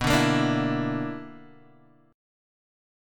BmM11 chord